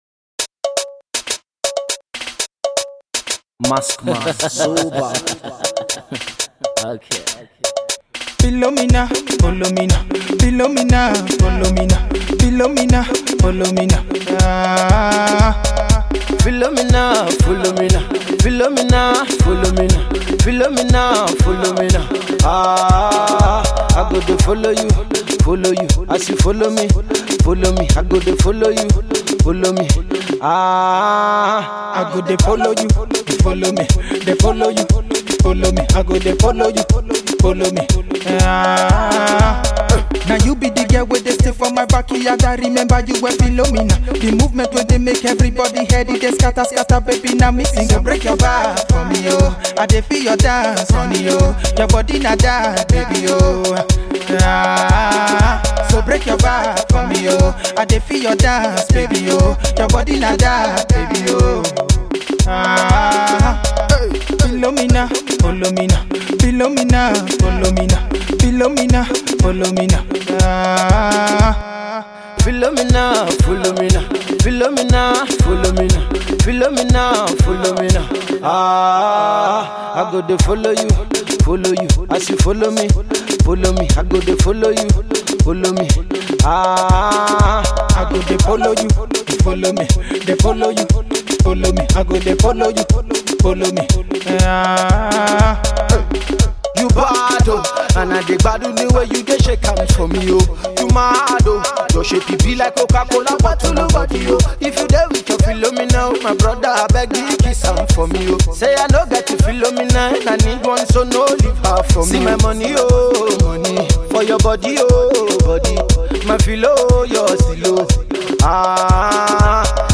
massive Afro Pop club smash
uber talented percussionist